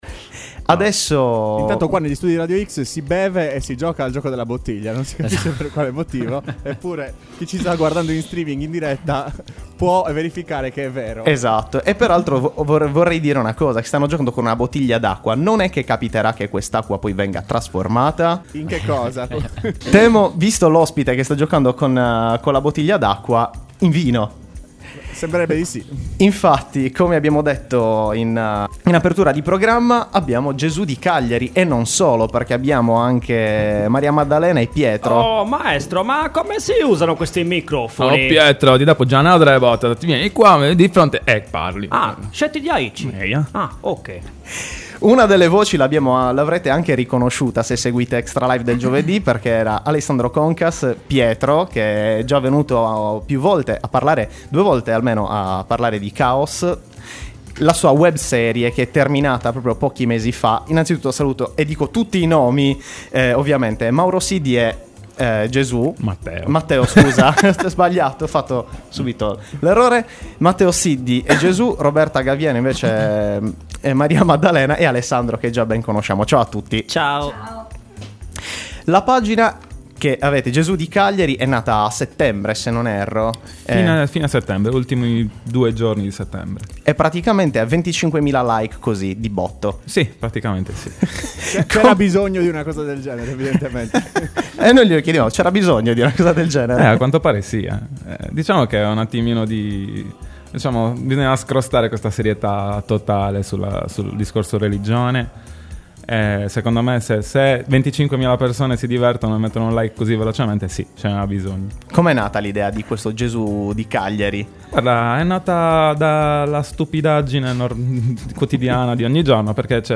A ExtraLIVE abbiamo chiacchierato con alcuni dei protagonisti: